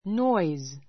nɔ́iz ノ イ ズ